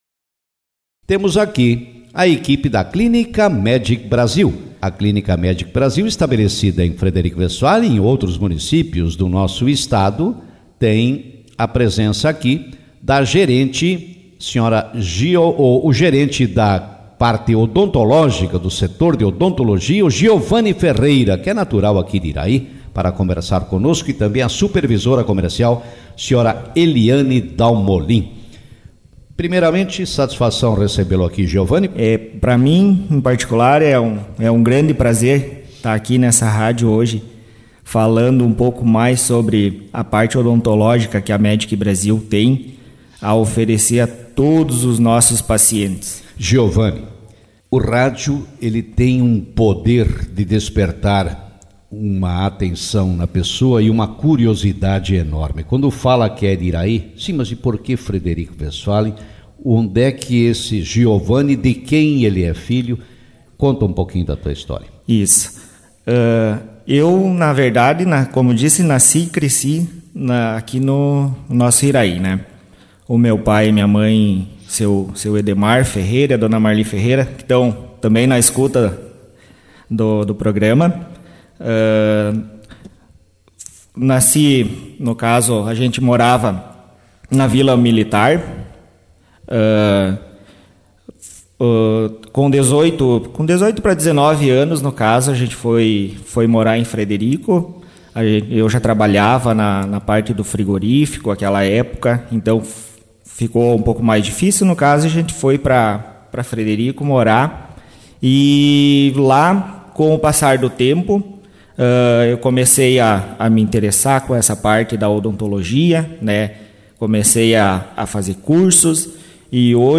Participaram nesta manhã, no programa Café com Notícias, profissionais que atuam na Clínica Médic Brasil. A empresa, especializada nem exames e procedimentos médicos, oferece planos anuais com descontos especiais.